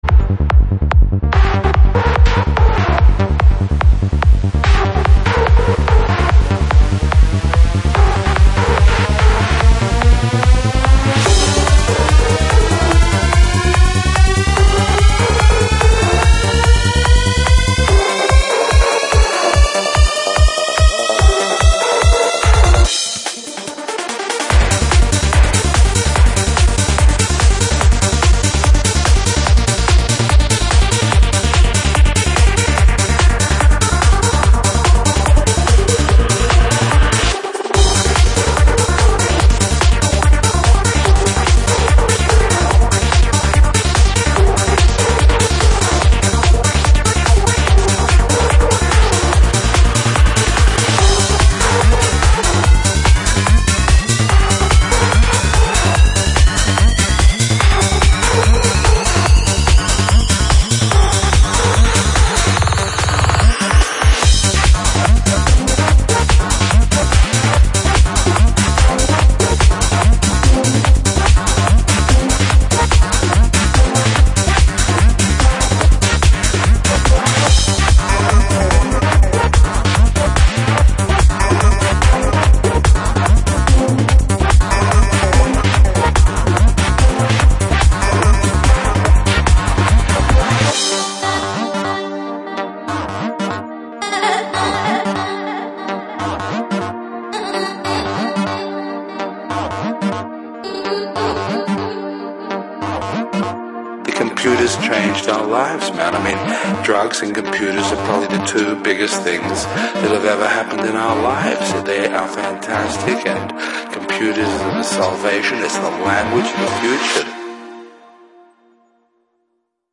Назад в ~Goa Psy Trance~
trance